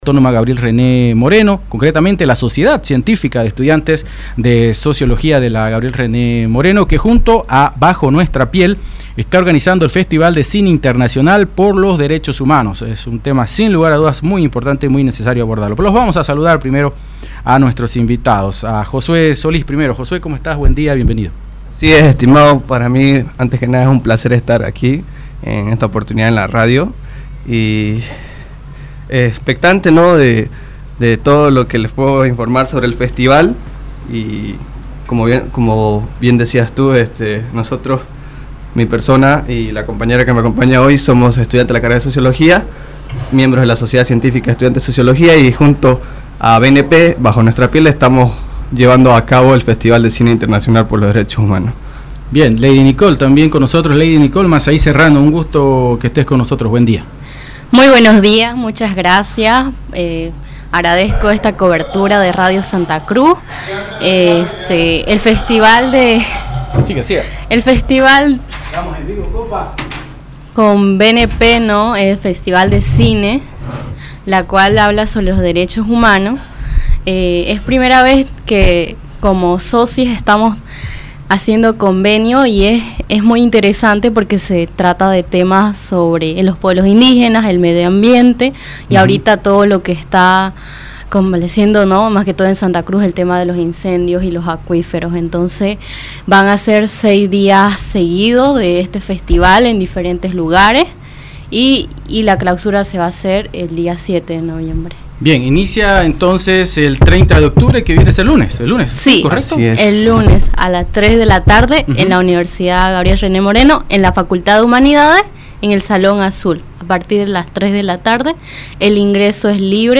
Escuche nuestra entrevista sobre el Festival.